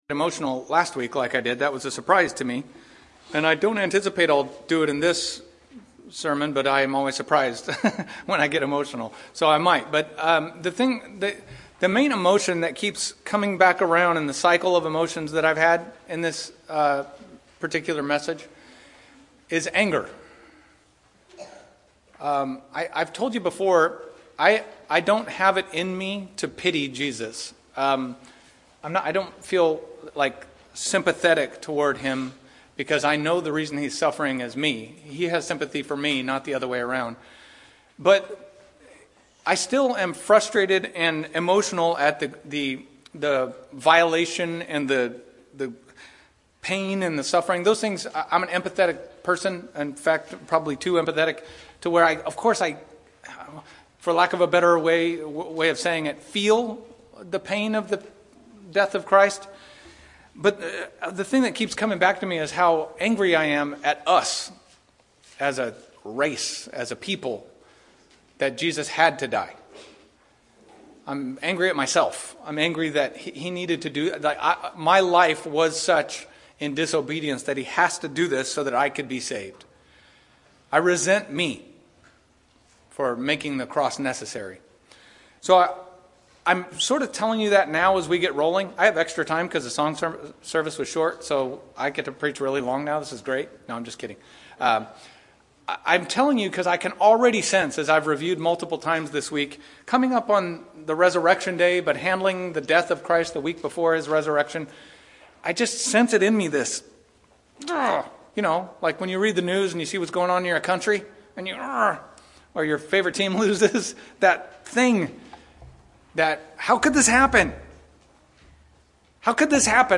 Sermons | Sovereign Grace Baptist Church